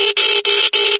Polaris / sound / items / geiger / ext4.ogg